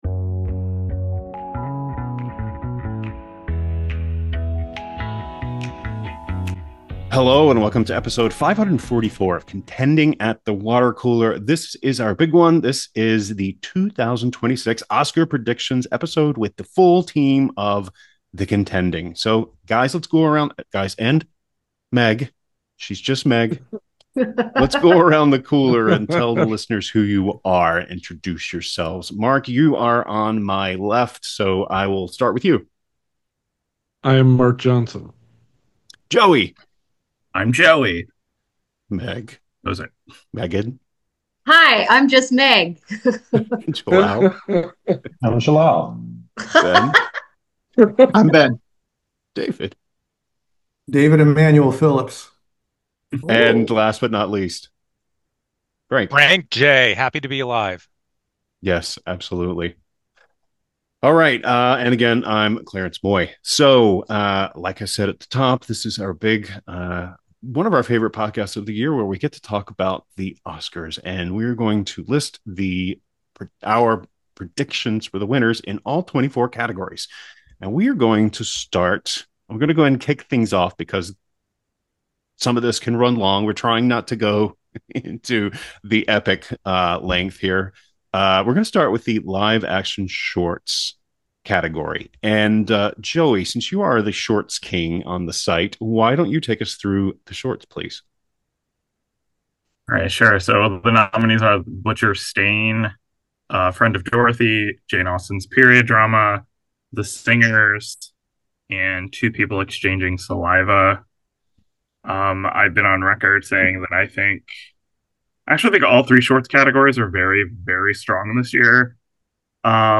The full roster at The Contending gathers around the Water Cooler to give their (somewhat) final 2026 Oscars predictions!